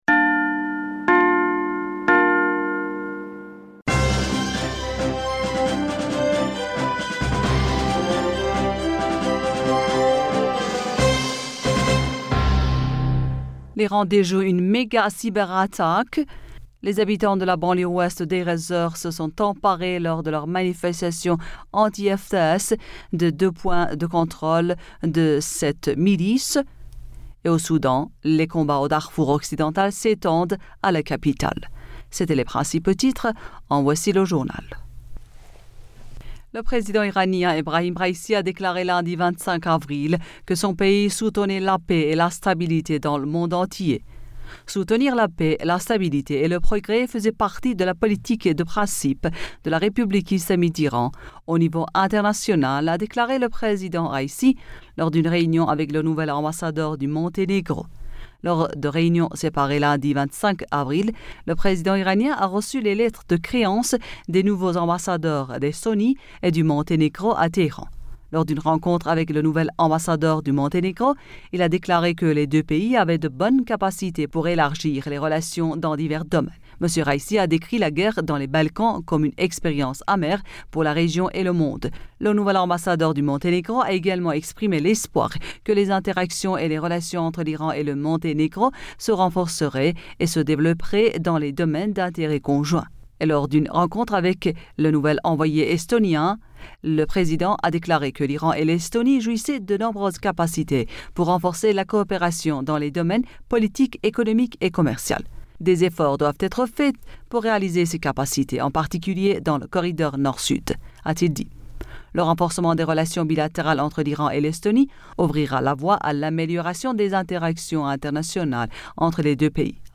Bulletin d'information Du 26 Avril 2022